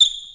Squeek1.mp3